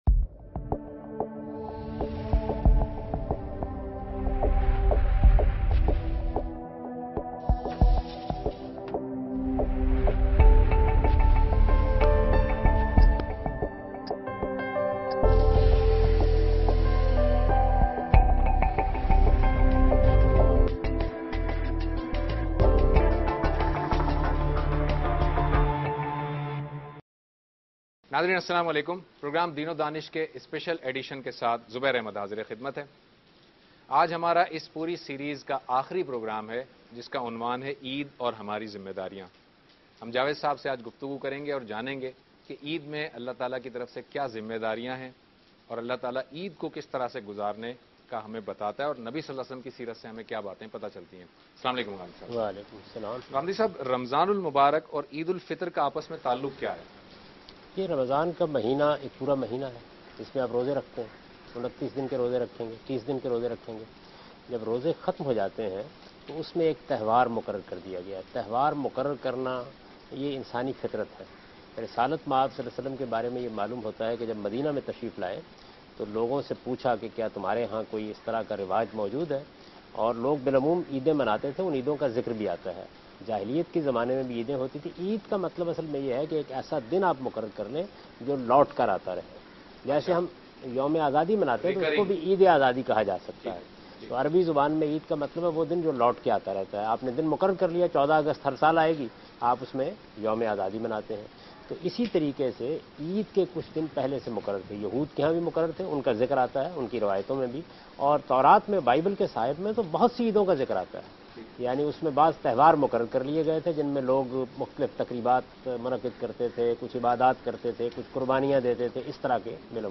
TV Programs
Category: TV Programs / Dunya News / Deen-o-Daanish / Questions_Answers /
دنیا ٹی وی کے پروگرام دین ودانش میں جاوید احمد غامدی عید اور ہماری ذمہ داریوں کے متعلق گفتگو کر رہے ہیں